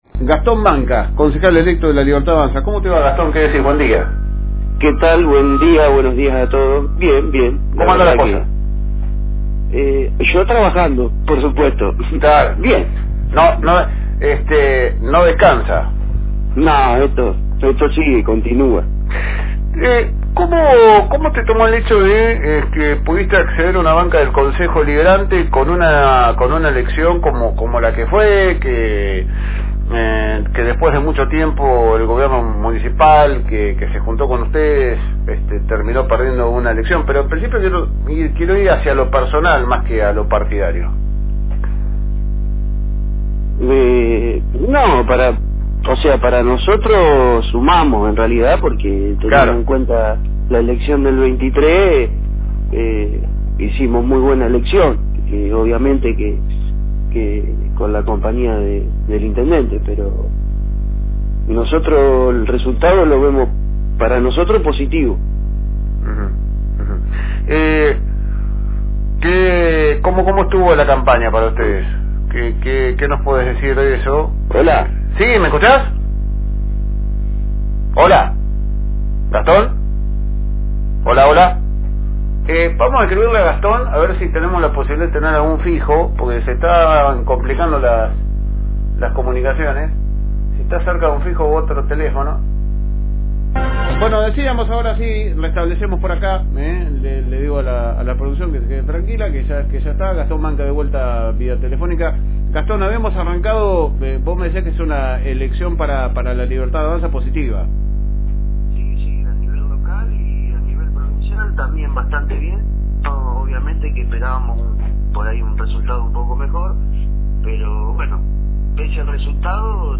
En Esto es noticia charlamos un rato con el nuevo concejal, que nos contaba un poco como ve el panorama político de acá a diciembre cuando tenga que ocupar su silla en el Concejo Deliberantes.